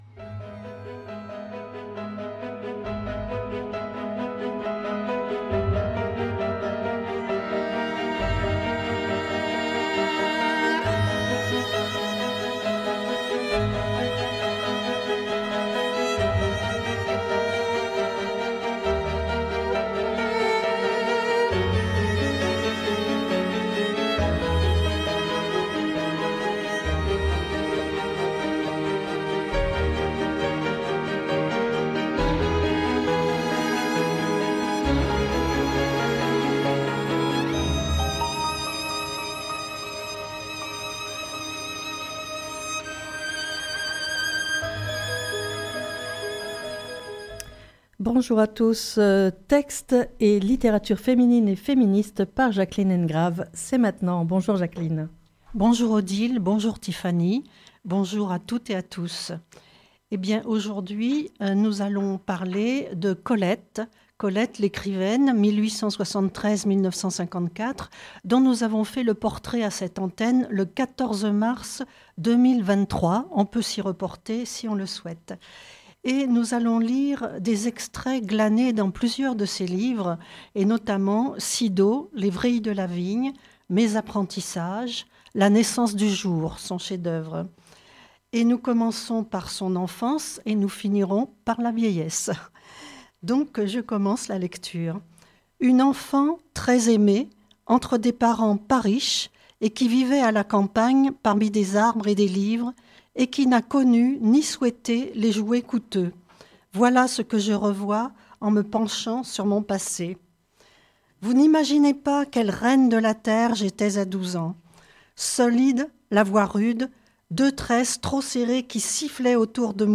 Lecture de textes et littérature féminine et féministe